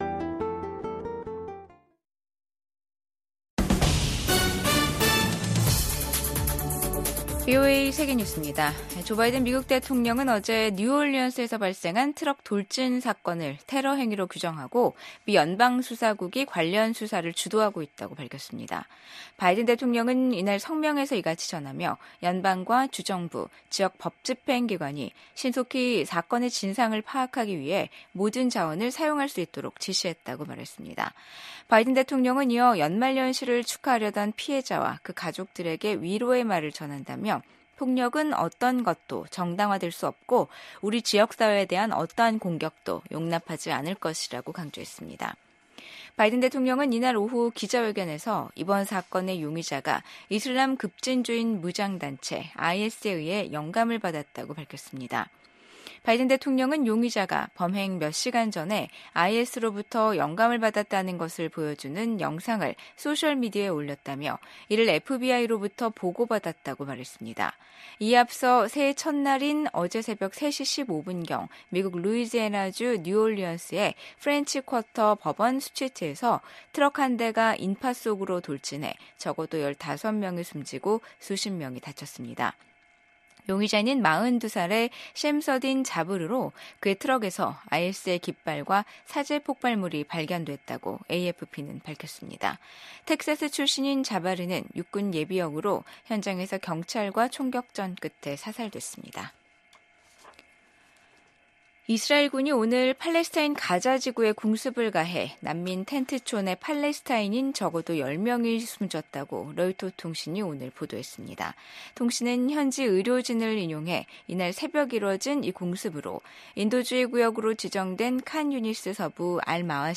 VOA 한국어 간판 뉴스 프로그램 '뉴스 투데이', 2025년 1월 2일 3부 방송입니다. 주한 미국 대사와 전현직 주한미군 사령관들이 신년사에서 미한동맹의 굳건함을 강조하며 동아시아 안보 강화를 위한 협력을 다짐했습니다.